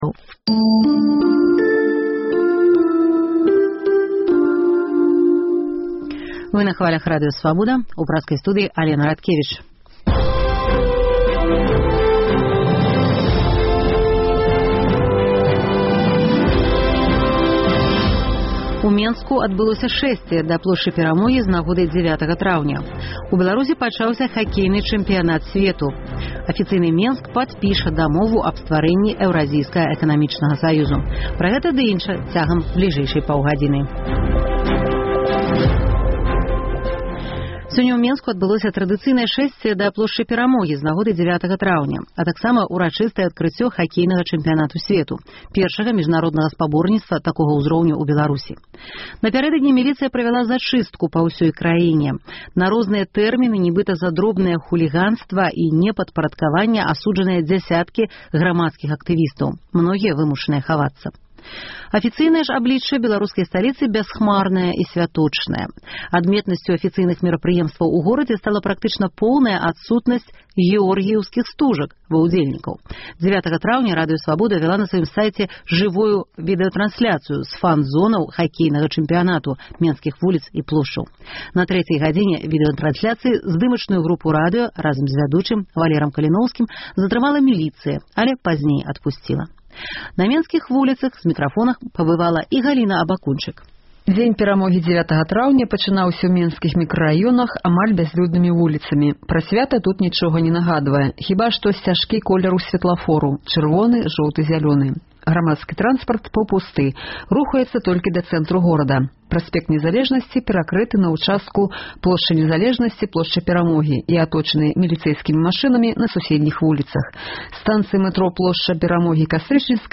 Міліцыя не дапусьціла і правядзеньня Рускага маршу. Пра тых, хто сьвяткуе і тых, каму не да сьвята – у жывым эфіры Свабоды.